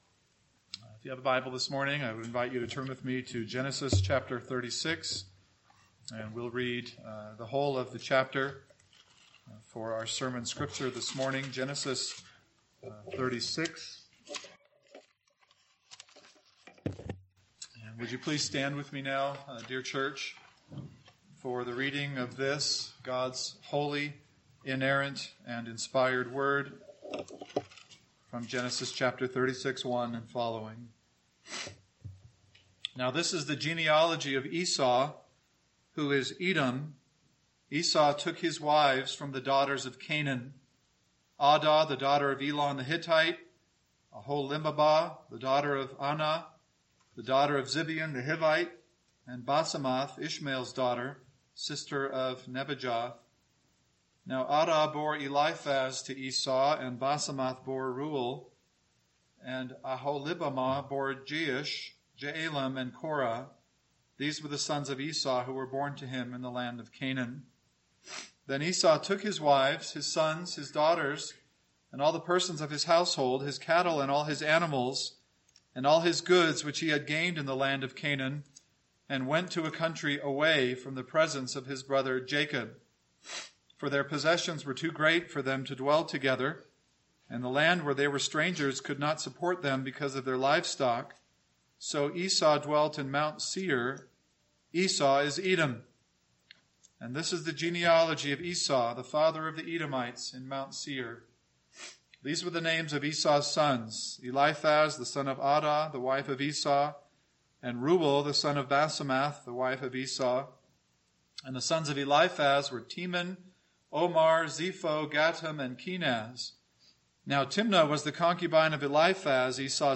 AM Sermon